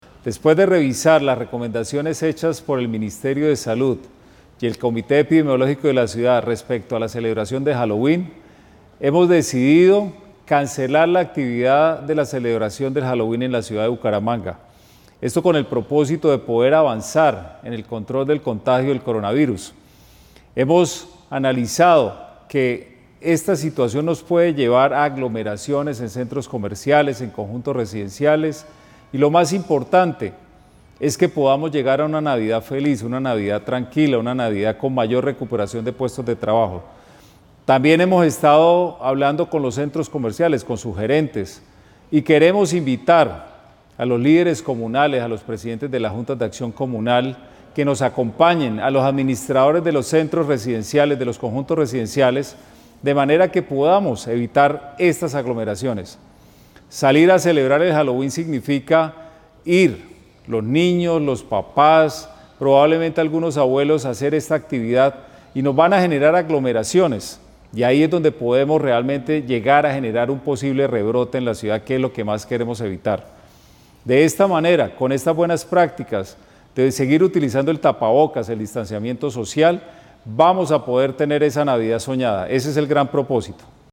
AUDIO-FULL-ALCALDE-HALLOWEEN-.m4v-1.mp3